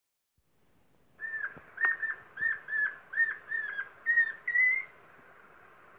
воробьиный сыч, Glaucidium passerinum
Примечания30.10.2021. plkst17:00 Gulbenes novada Stāmerienas pagasta Kalnienā, pie "Cepļakalnu" mājām novērots mājas apogs.